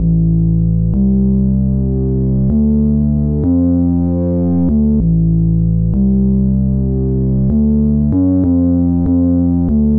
贝斯样本 " 贝斯前进1 4小节 96 BPM
Tag: 电子 合成器 音乐样本 fruityloops 低音